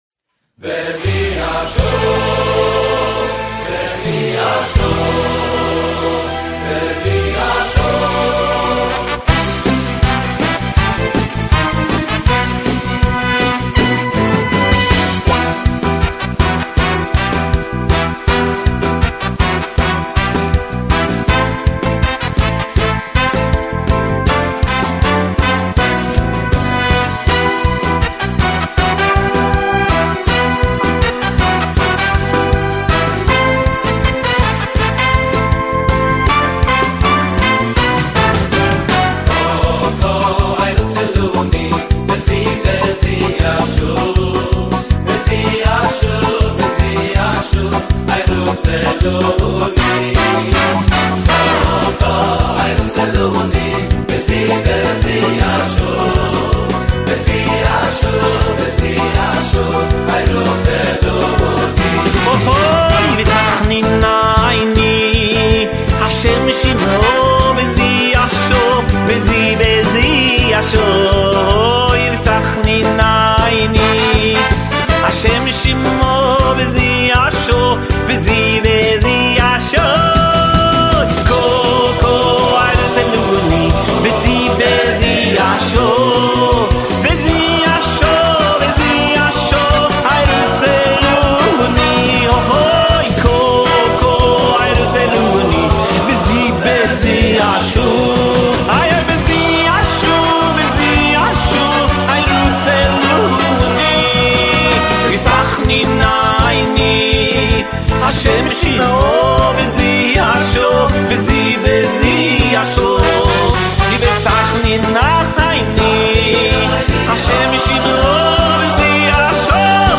גיליתי זמר נהדר ומכר טוב, שפשוט שר מדהים!
מחרוזת חסידית.mp3